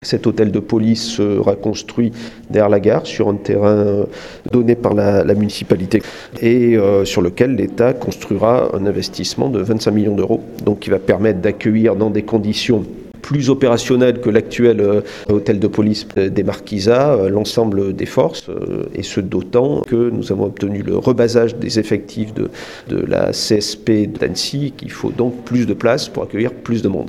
Alain Espinasse, préfet de la Haute Savoie nous parle de ce projet.